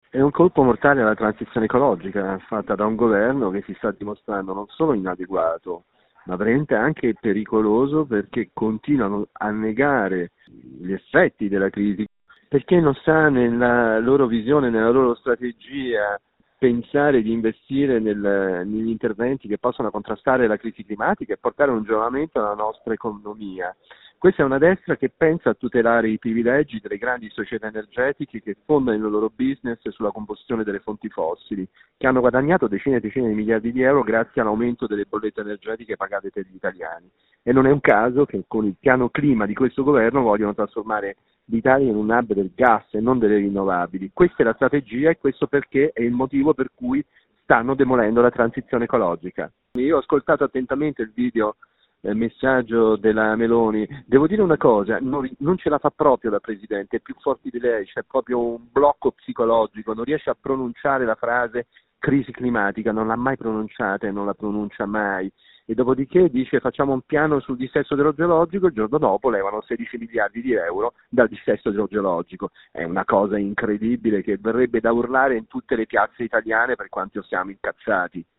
“Un colpo mortale alla transizione ecologica”, dice ai nostri microfoni Angelo Bonelli, coportavoce di Europa Verde